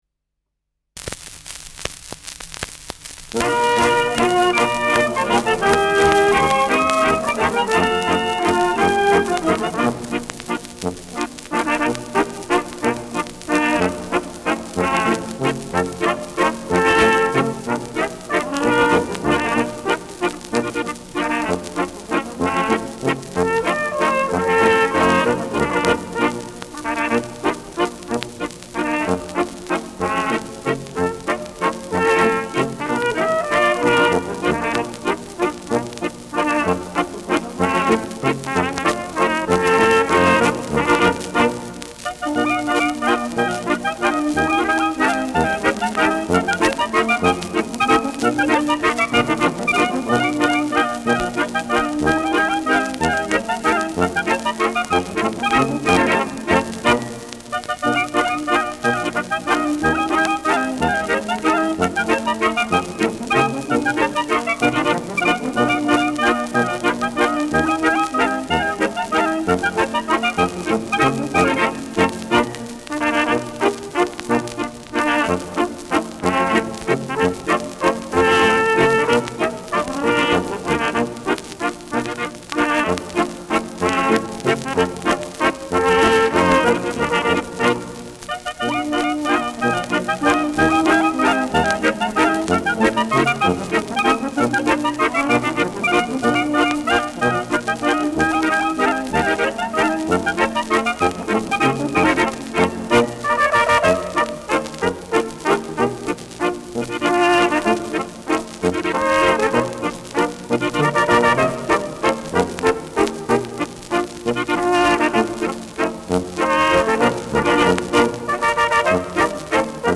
Schellackplatte
leichtes Rauschen